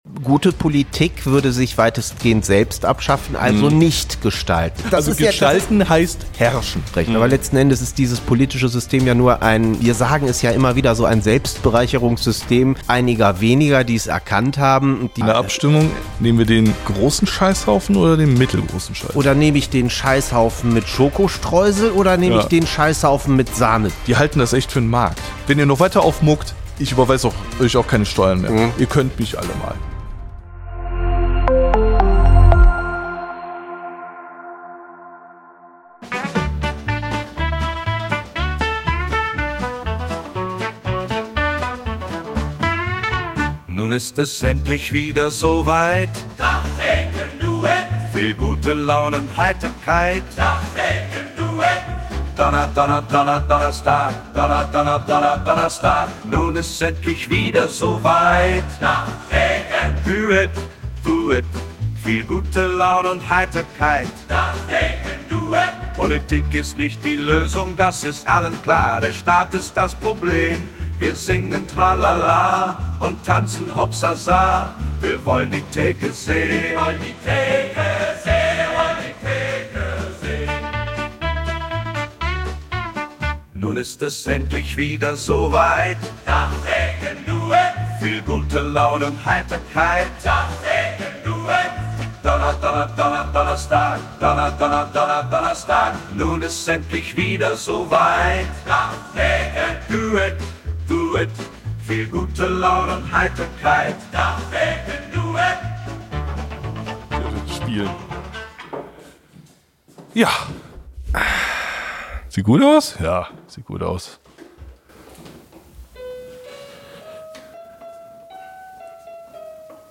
Kleine Redaktionskonferenz am Tresen